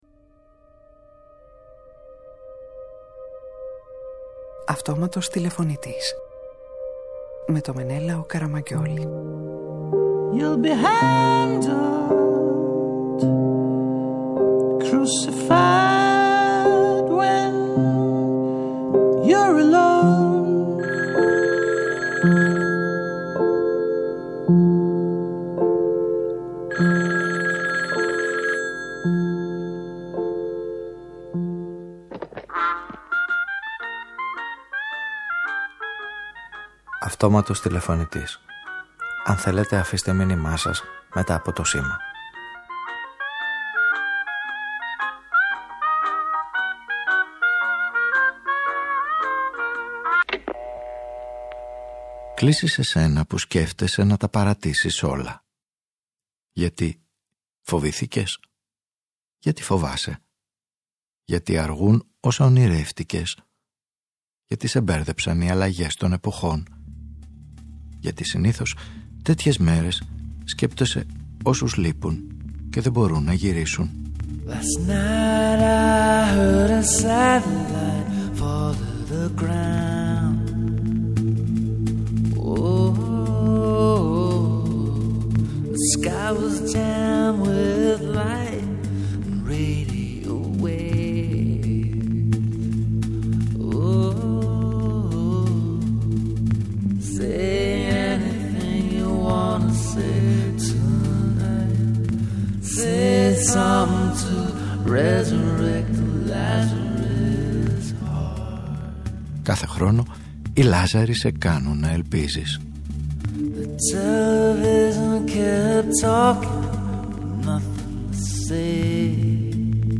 ΤΟ ΧΕΡΙ ΤΟΥ ΛΑΖΑΡΟΥΗ σημερινή ραδιοφωνική ταινία, επαναλαμβάνοντας τα ανοιξιάτικα μυστήρια, αποτυπώνει το δεξί χέρι του νεκρού Λάζαρου, όπως το ζωγράφισε ο Καραβάτζιο πριν πεθάνει: Ανεξάρτητα από το νεκρό σώμα αρχίζει να αυτονομείται και να διεκδικεί τη ζωή· αυτή τη ζωή που μπορεί να απλωθεί σε ένα πεθαμένο κορμί και να το αναστήσει.